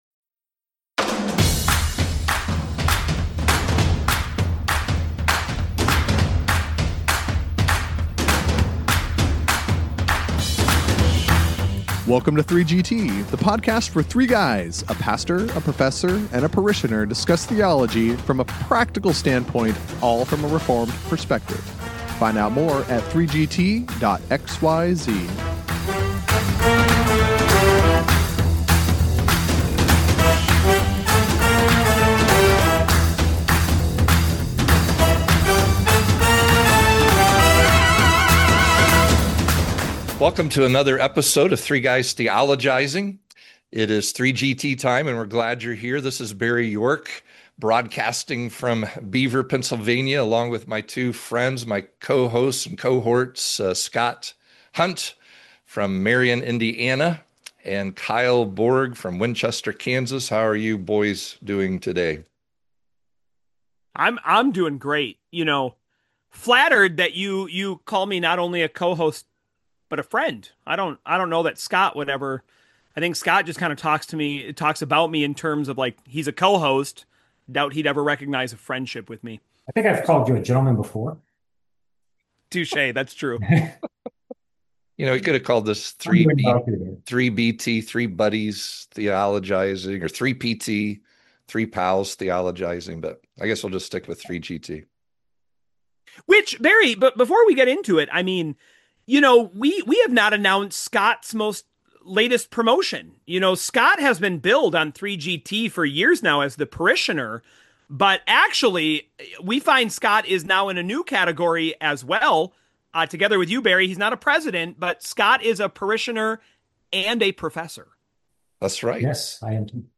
The three guys (see: friends, pals, buds) are back this week and speaking about a forbidden topic!